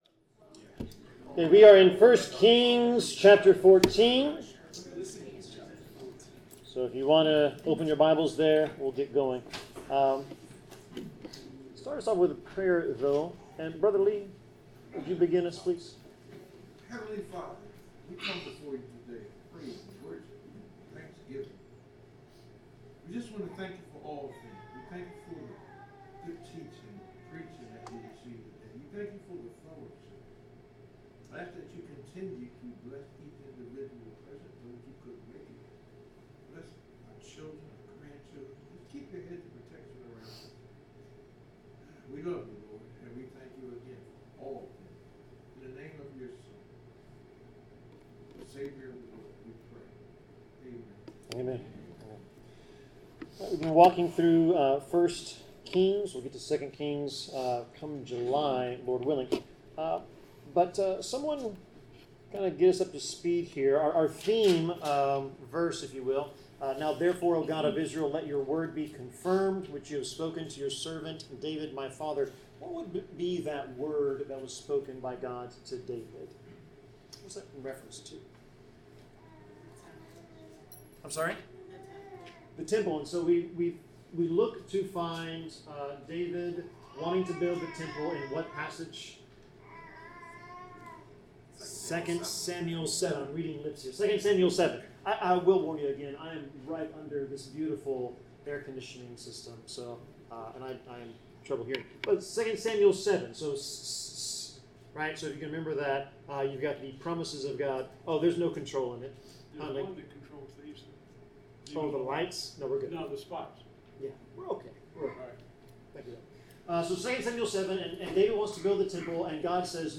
Service Type: Bible Class